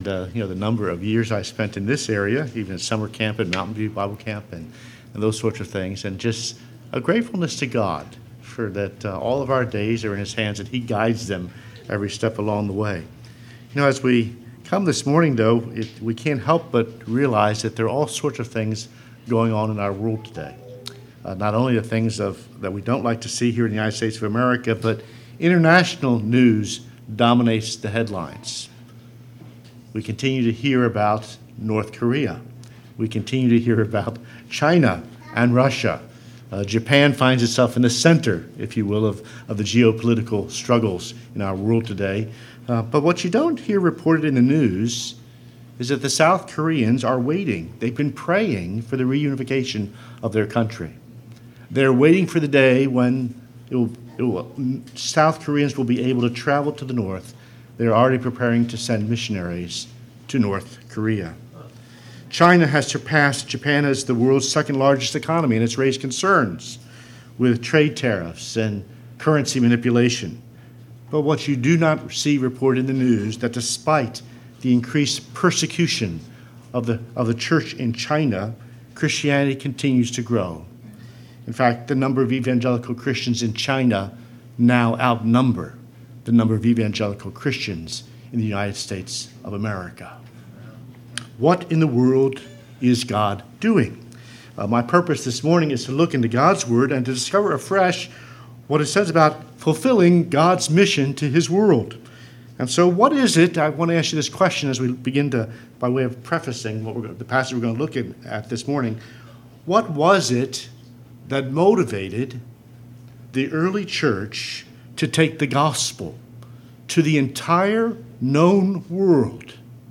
All Sermons What in the World is God Doing?, Acts 1:6-11
Series: Guest Speakers